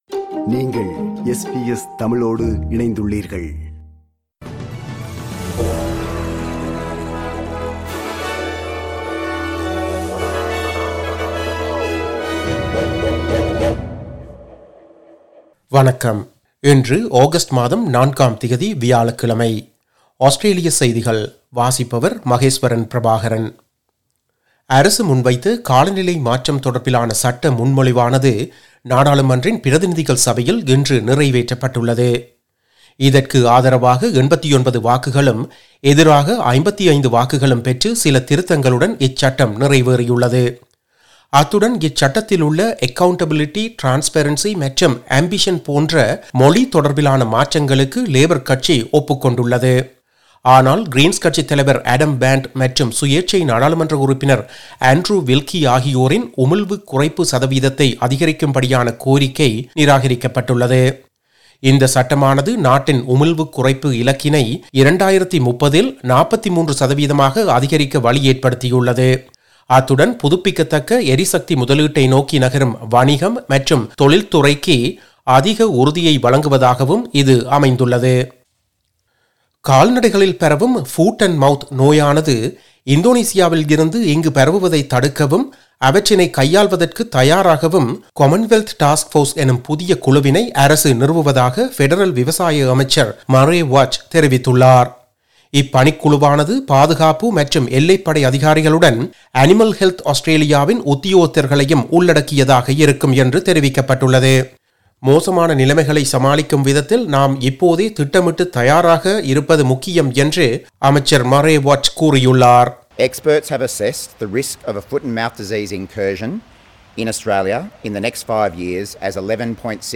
SBS தமிழ் ஒலிபரப்பின் இன்றைய (வியாழக்கிழமை 04/08/2022) ஆஸ்திரேலியா குறித்த செய்திகள்.